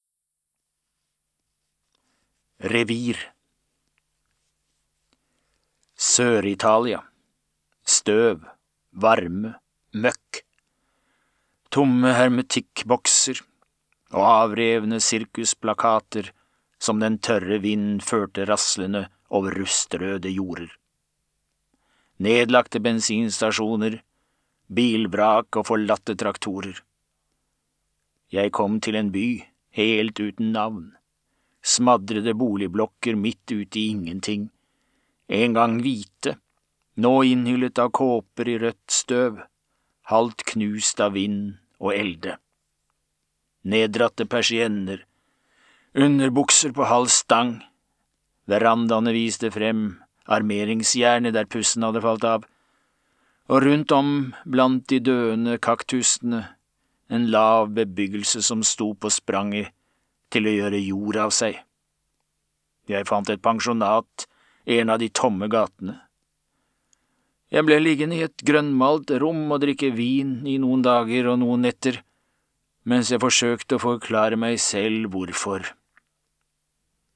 Revir (lydbok) av Ingvar Ambjørnsen